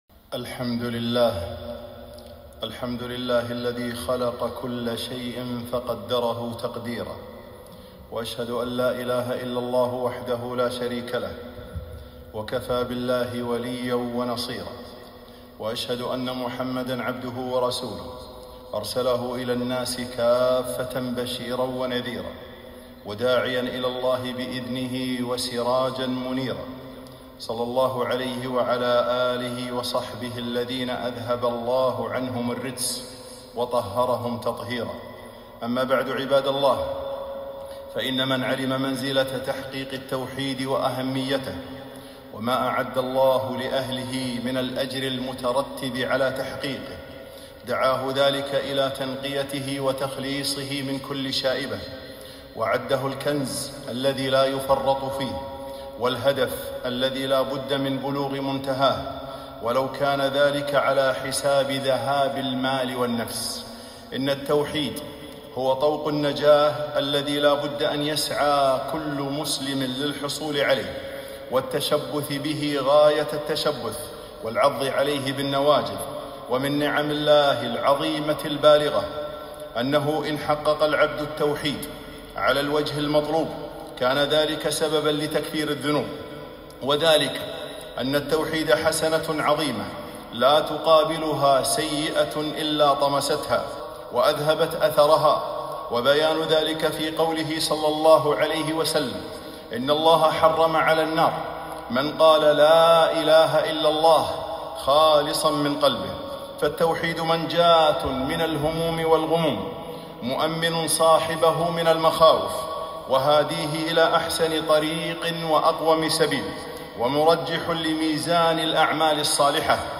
خطبة - التمسك بالتوحيد